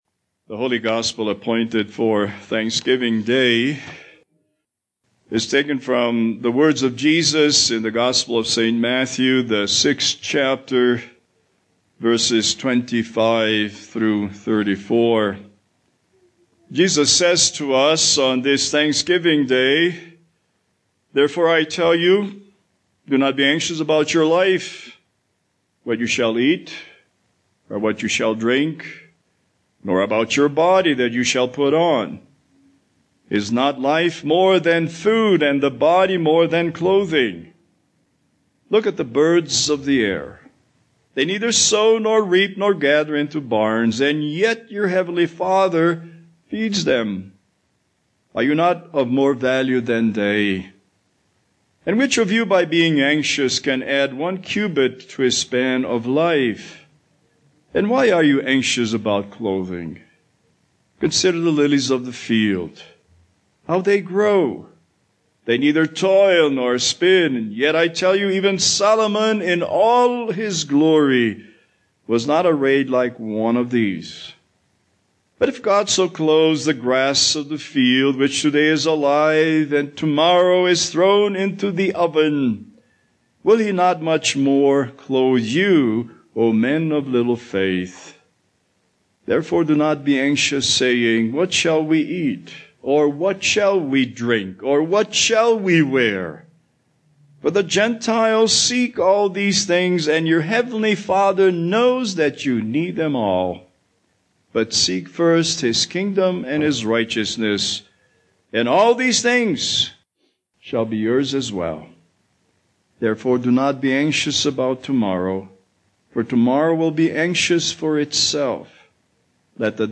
Holiday Sermons Passage: Matthew 6:25-34 Service Type: Thanksgiving Service